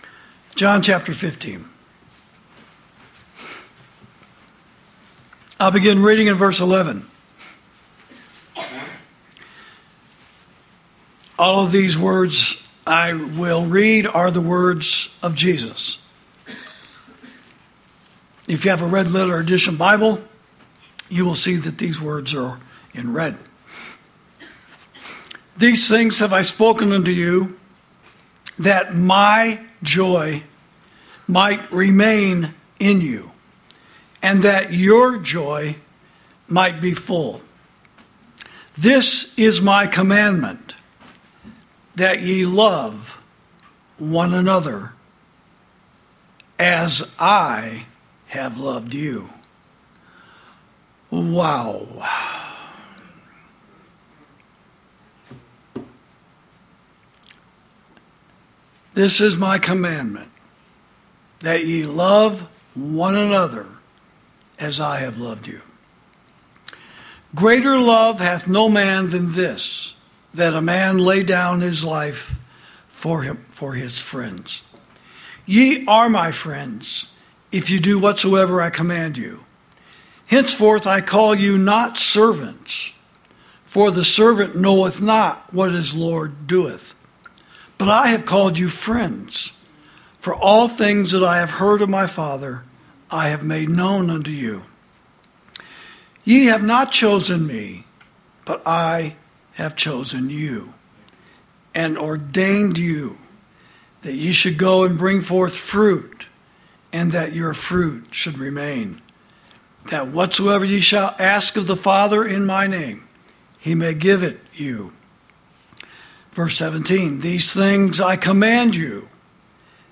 Sermons > Christian Brotherhood Extends To Gaza, The West Bank, Lebanon And Syria